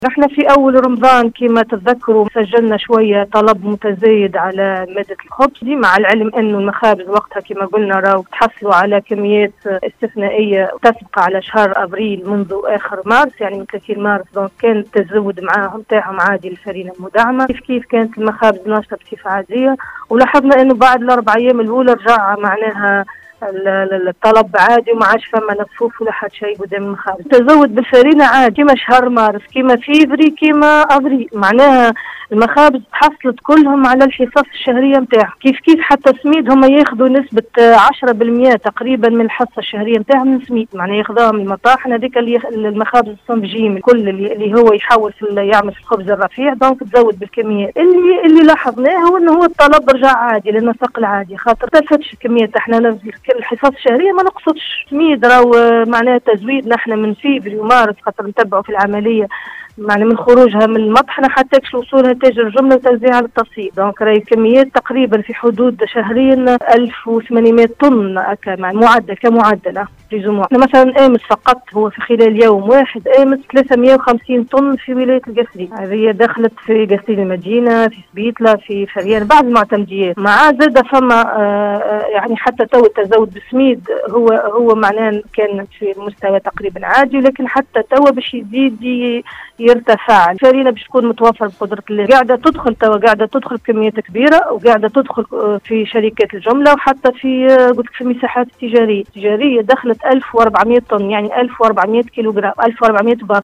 أكدت المديرة الجهوية للتجارة وتنمية الصادرات بالقصرين، سامية البريكي،  في تصريح لإذاعة السيليوم أف أم  صباح اليوم الأربعاء 20 أفريل 2022 ببرنامج القصرين و أحوالها ، أن المساعي متواصلة لتحسين وتعديل نسق التزويد بالمنتوجات الحساسة التي يكثر عليها الاستهلاك، لاسيما مادتي السميد والفارينة، وذلك بمختلف معتمديات الجهة.